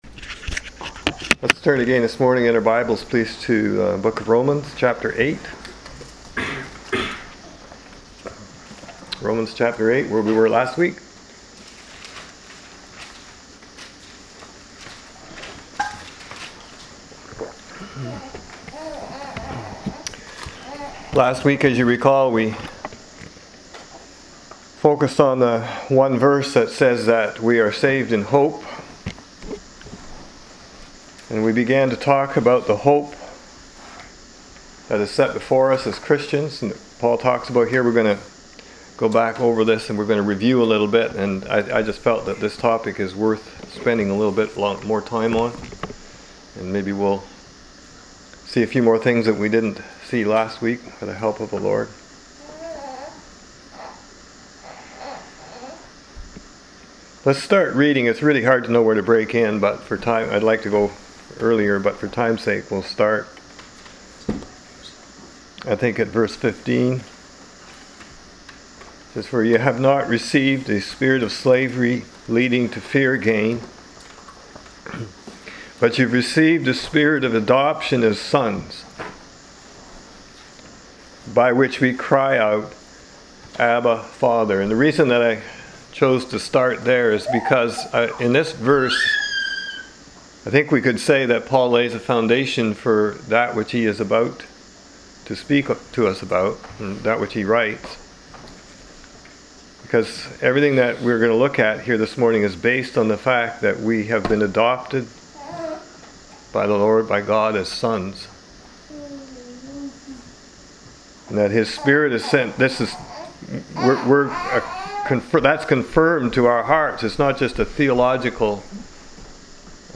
Click on Links below for articles quoted in this sermon: